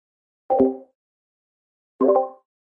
Звуки Discord
Микрофон выключили и снова включили